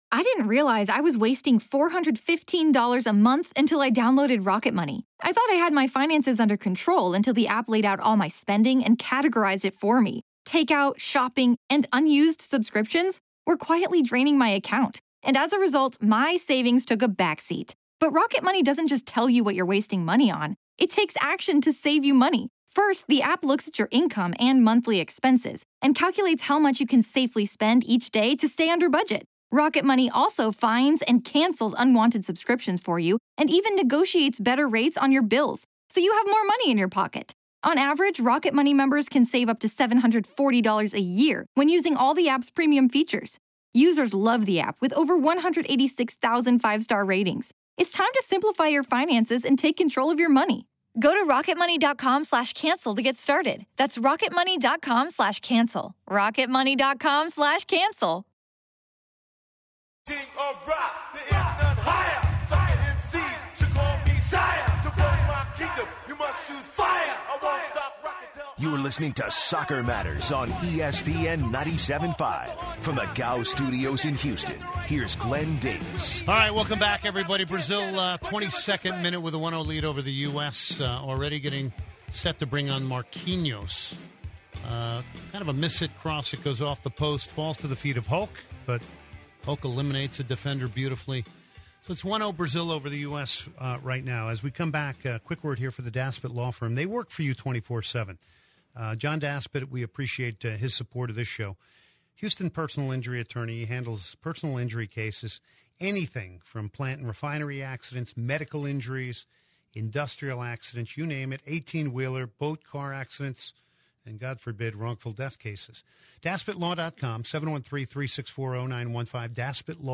Landon Donovan, US Men's National team legend, joins the show to discuss his time in European football, his feelings behind not going to the 2014 World Cup, and his desire to remain in the sport of soccer.